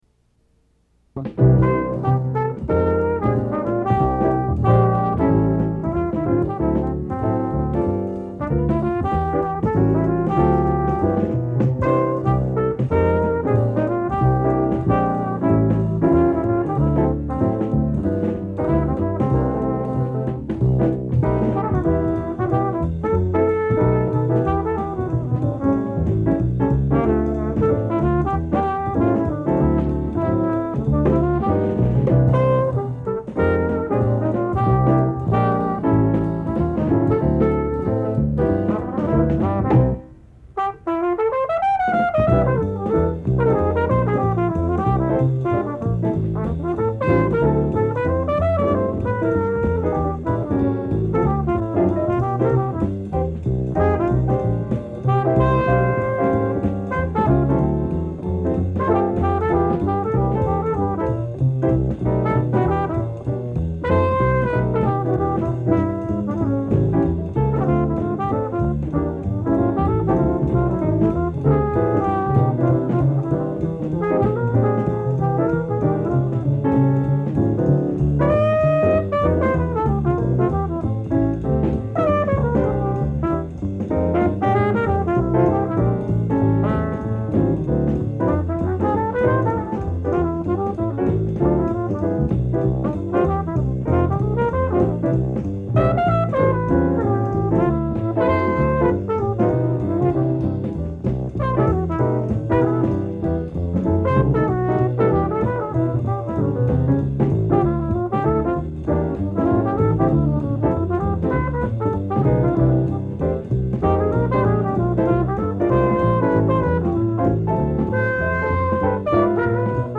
flugelhorn